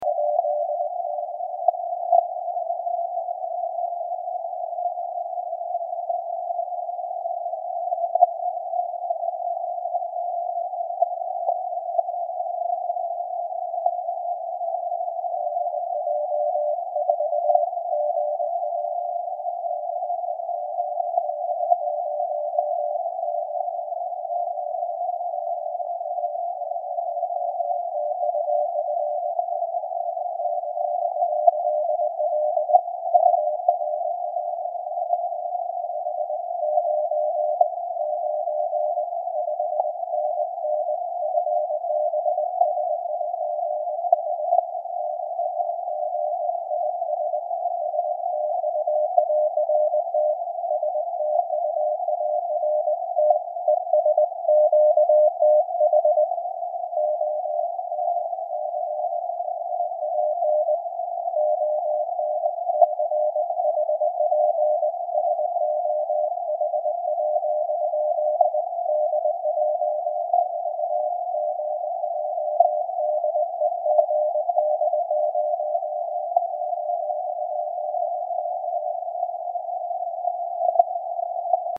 Запись CW делал при приеме слабого сигнала (даже не знаю что за позывной :) ). PRE был включен. Все записи с линейного выхода. Фильтр при CW 50 Гц.
На записи CW в твоём файле, телеграфный тон "грязный" скрипящий - с гармониками. Это очень хорошо слышно с 53-й по 56-ю секунду записи, когда уровень сигнала возрастает.